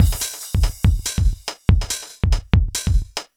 Index of /musicradar/uk-garage-samples/142bpm Lines n Loops/Beats
GA_BeatA142-08.wav